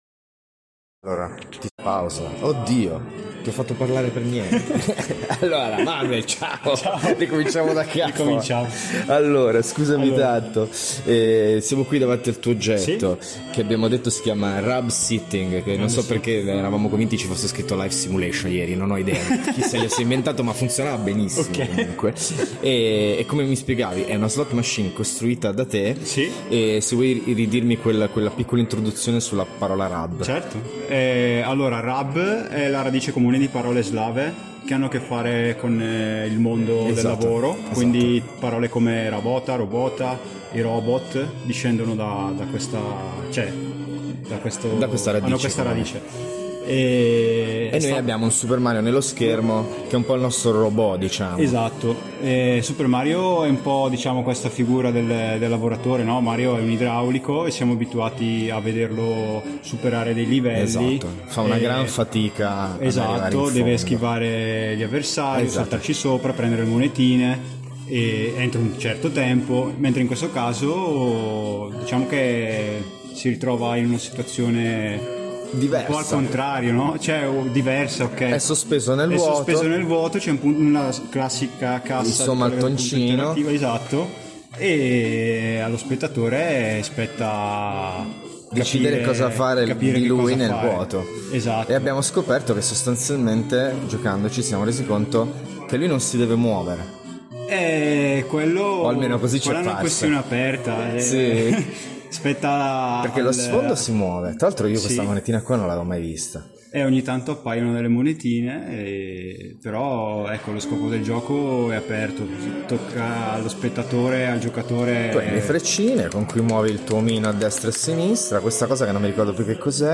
Intervista puntata Radio Warpa - Radio Antidoto, in occasione della tappa torinese di Zona Warpa